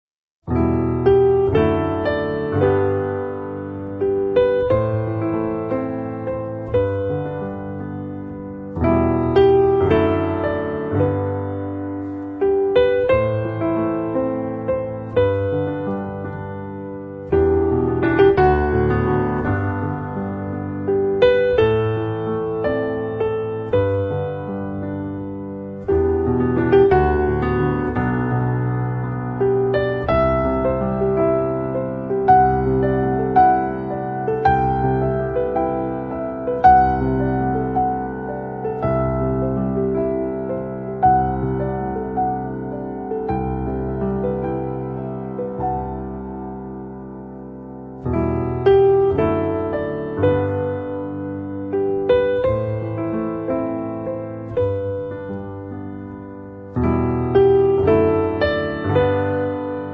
pianoforte
Un album che si avvicina sicuramente più al pop che al jazz.